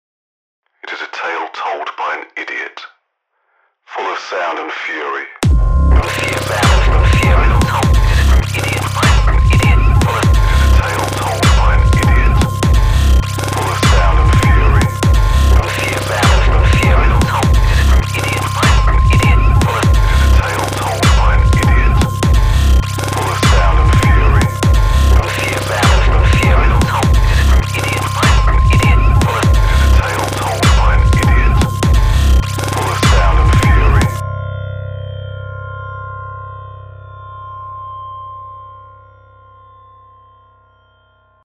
Не торчит ли снейр.
Друзья! Очень прошу послушать на вашем контроле этот фрагмент, и с сказать, не торчит ли снейр..